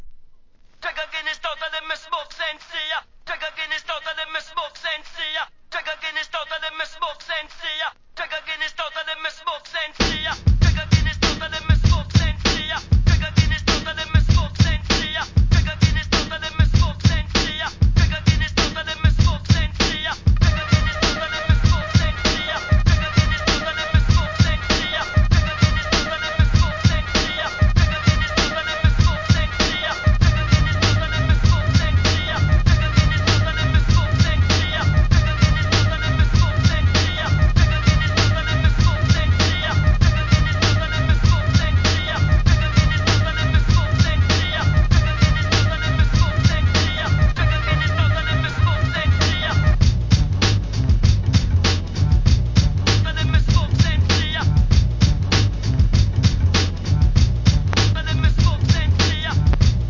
ハードかつオシャレな真っ黒JAZZYブレイクビーツ！！